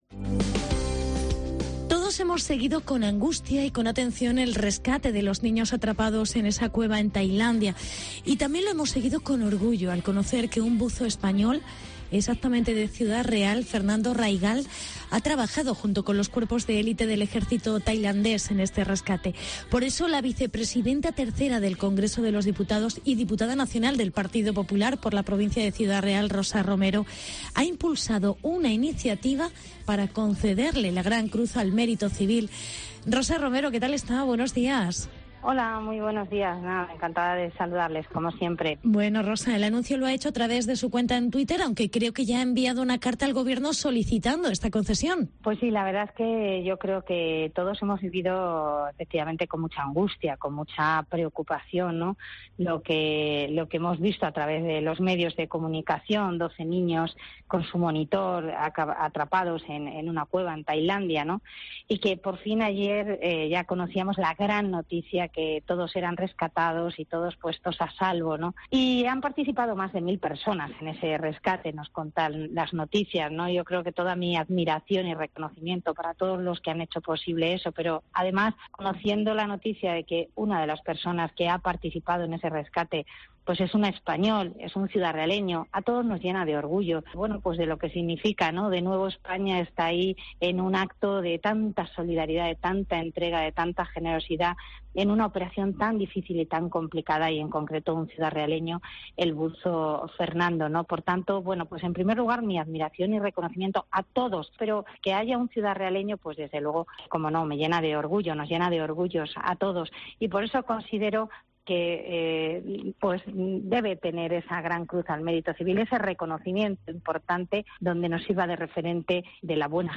Hablamos con la vicepresidenta tercera del Congreso